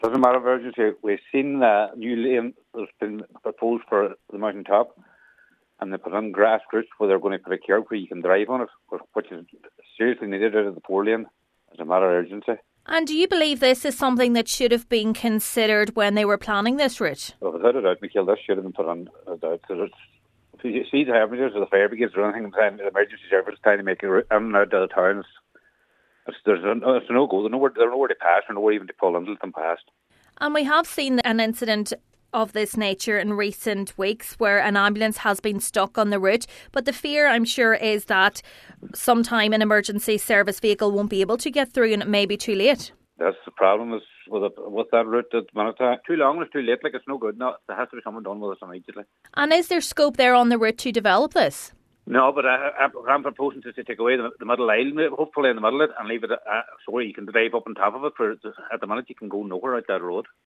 He says consideration of the movement of emergency services on the Four Lane route should have been given at planning stage: